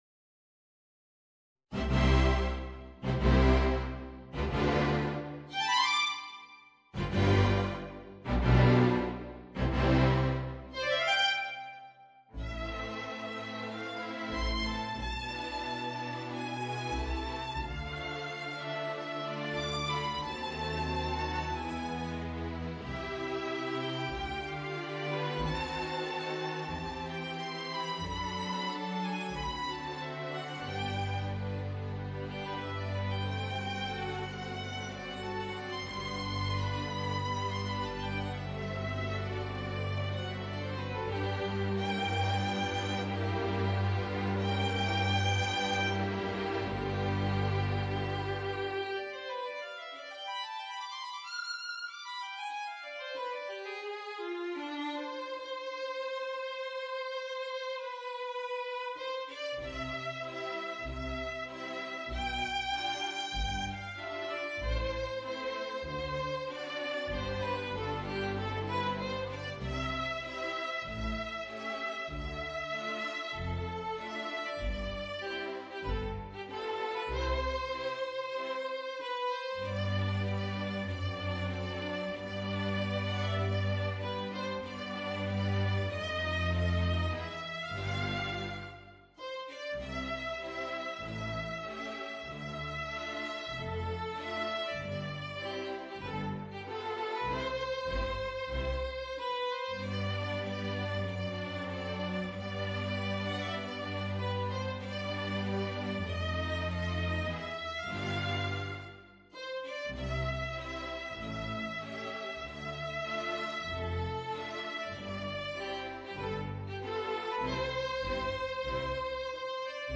Voicing: Flute and String Orchestra